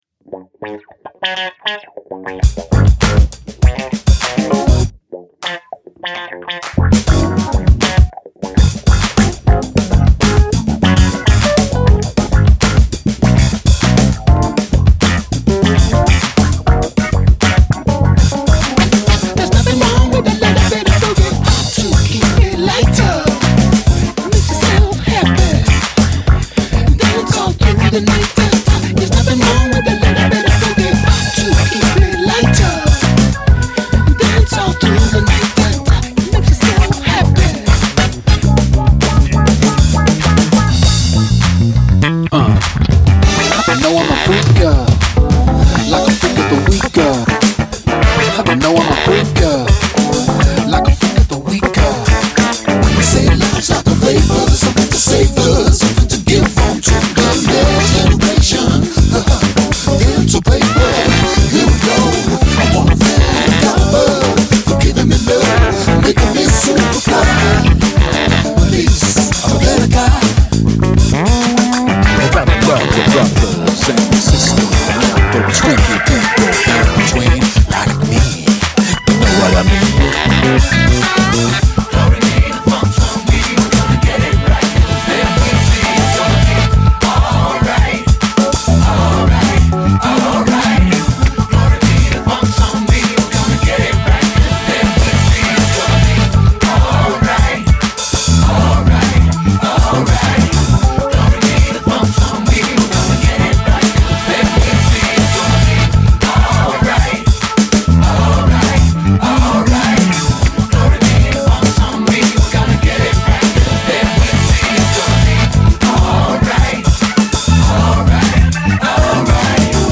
“Global Funk Sounds”